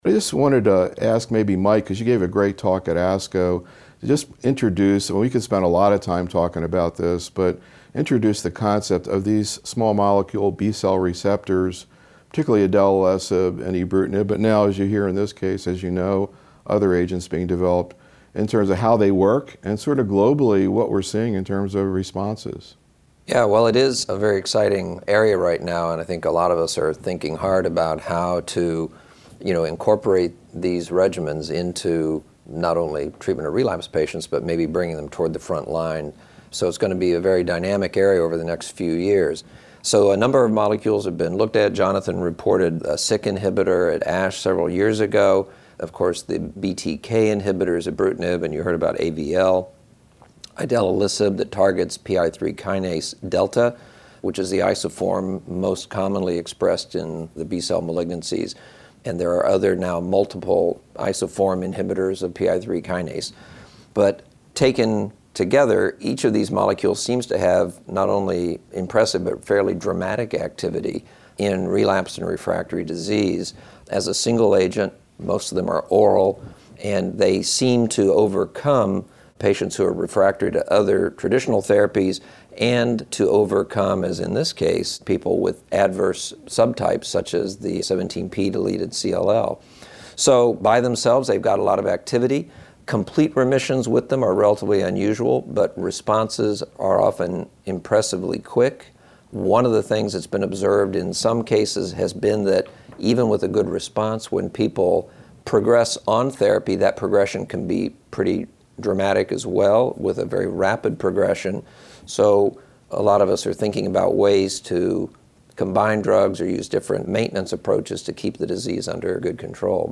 The roundtable discussion focused on key presentations and papers and actual cases managed in the practices of the faculty where these data sets factored into their decision-making.